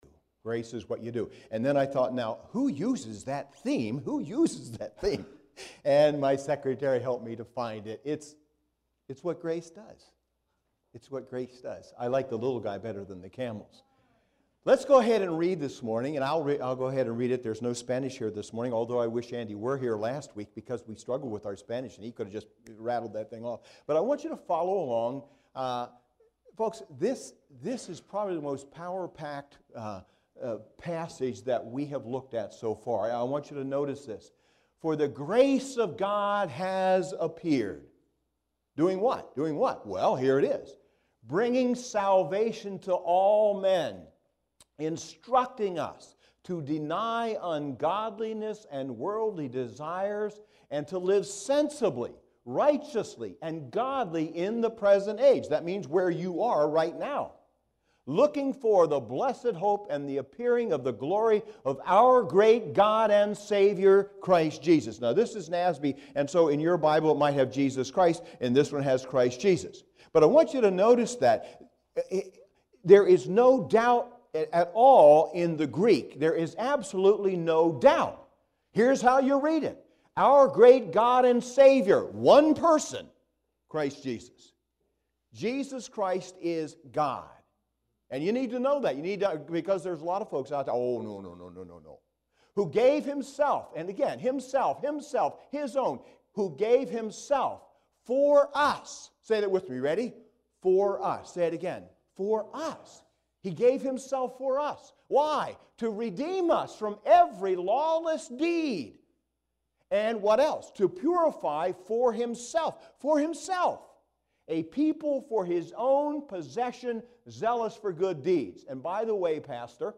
SERMONS | Grace Fellowship Church